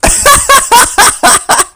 Goofy Ahh Laugh Haha Sound Button - Free Download & Play
Reactions Soundboard338 views